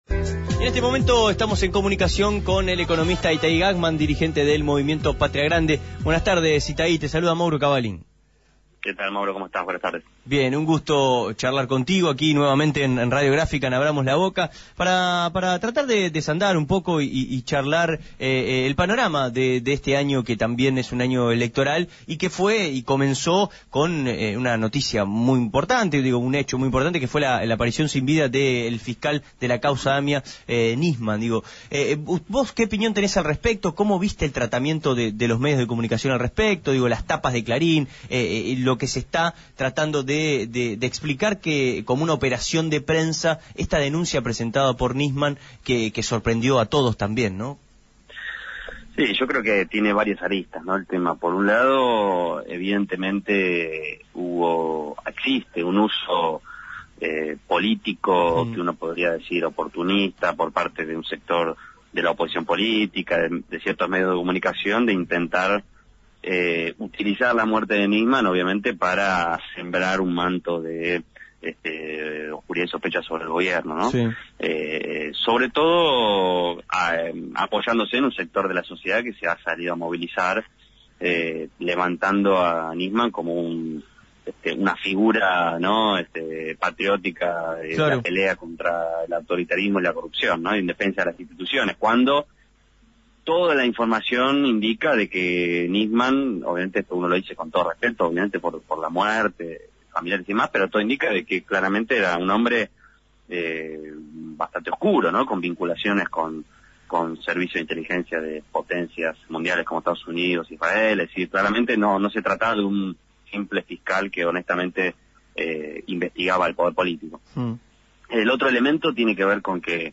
El referente de Patria Grande, el economista Itaí Hagman, conversó con Radio Gráfica sobre la coyuntura política que abrió el caso Nisman.